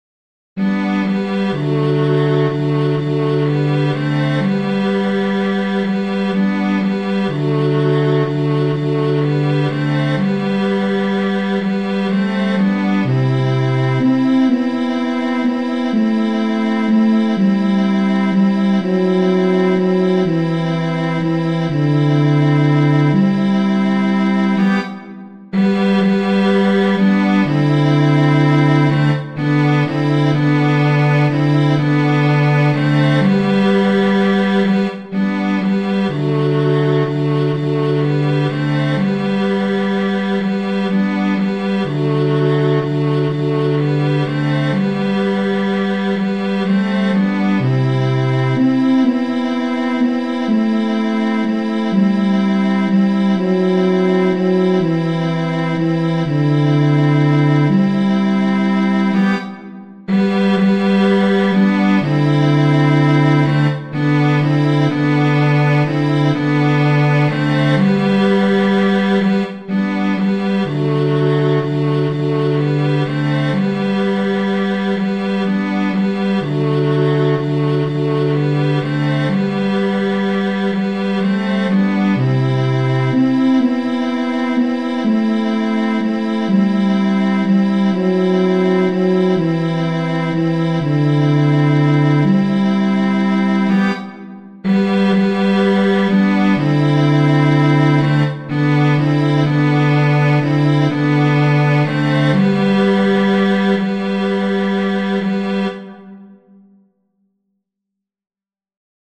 Musiche digitali in mp3 tratte dagli spartiti dell'opuscolo